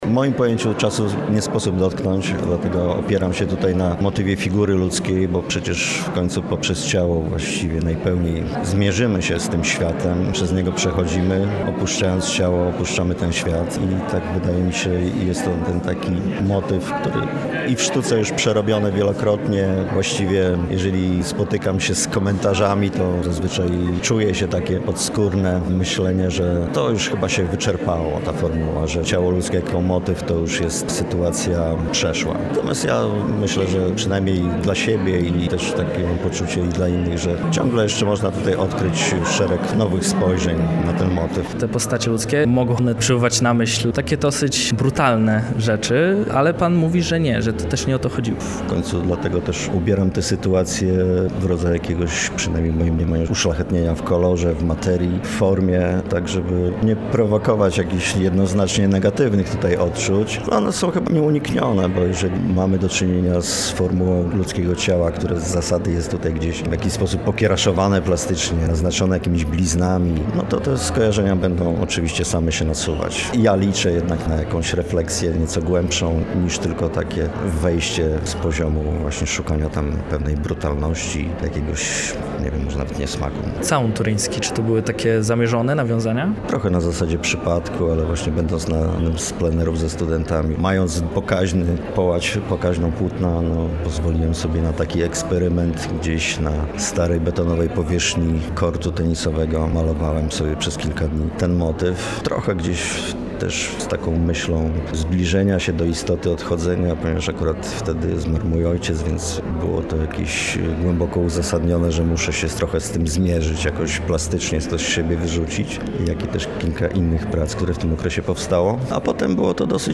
Wernisaż wystawy „Na ścieżkach czasu” odbył się w środę 4 marca.
0903-wystawa-stara-kotlownia.mp3